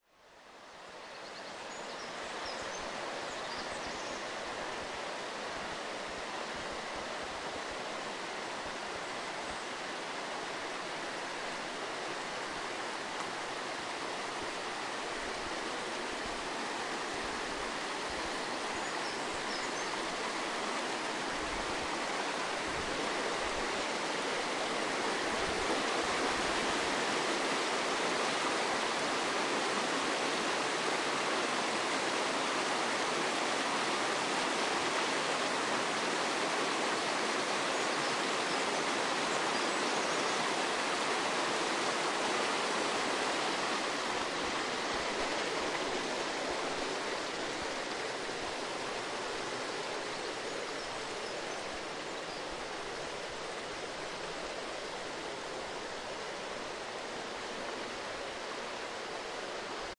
描述：这段录音是1999年5月31日在苏格兰珀斯郡的德拉蒙德山上完成的，从凌晨4点开始，使用森海塞尔MKE 66加上索尼TCDD7 DAT录音机与SBM1设备。
Tag: 鸟类 鸟鸣 农村 dawnchorus 现场录音 上午 性质 苏格兰